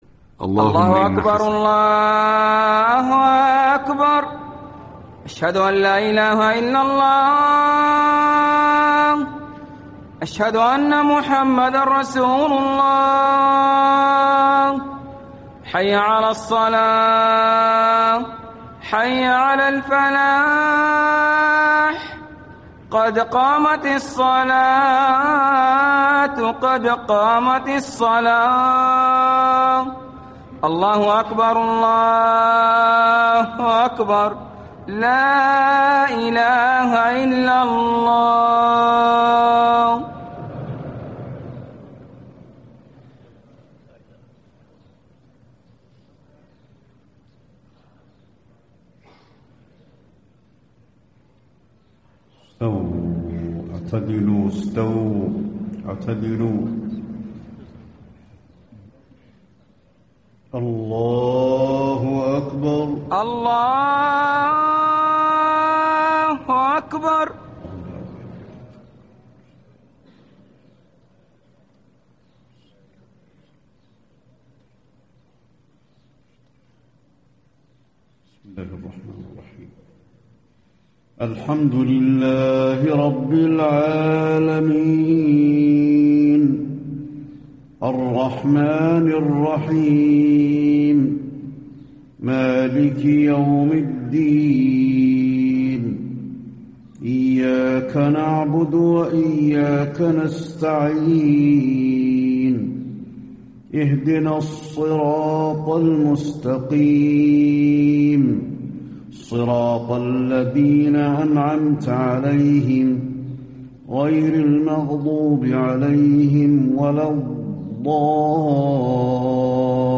صلاة العشاء 8-2-1435 آواخر سورة الأنبياء > 1435 🕌 > الفروض - تلاوات الحرمين